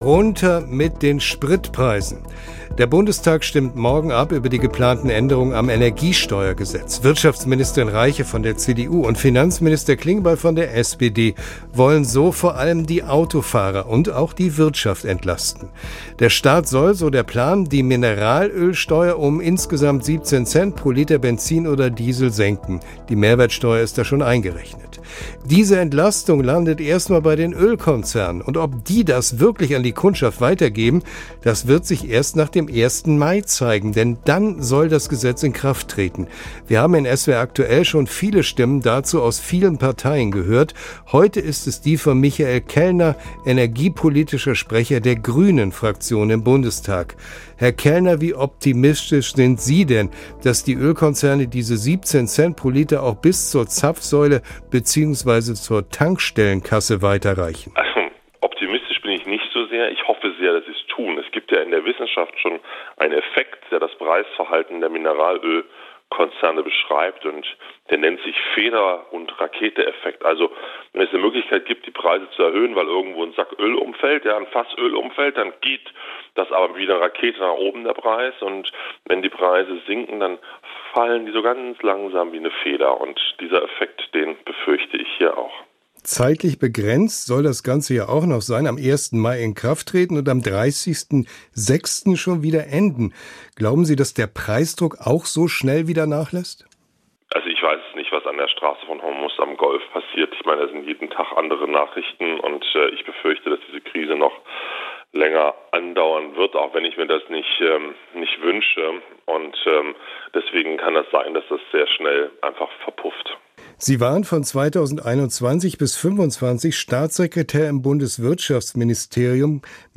"Ich glaube nicht, dass da adäquat gehandelt wird", sagt er im Gespräch mit SWR Aktuell. Statt die Ölpreise zu senken, müsse seiner Ansicht nach der Verbrauch runtergehen, da die Mengen auf dem Weltmarkt limitiert seien.